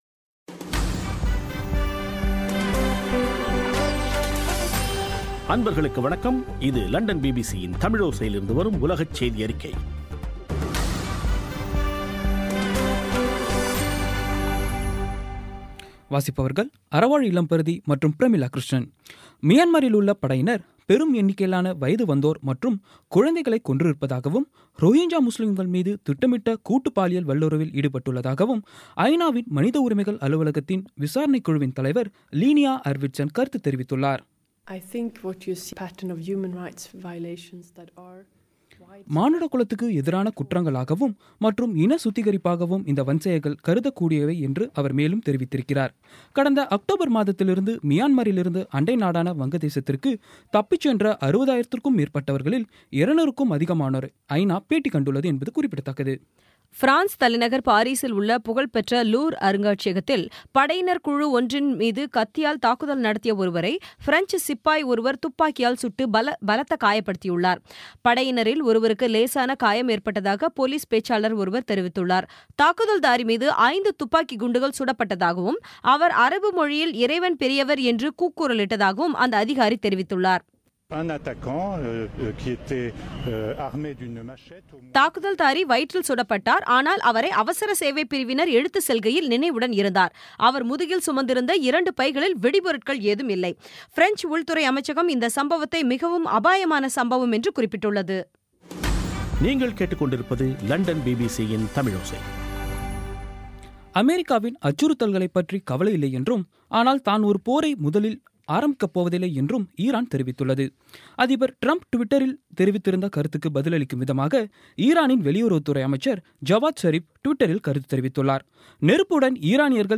பிபிசி தமிழோசை செய்தியறிக்கை (03/02/17)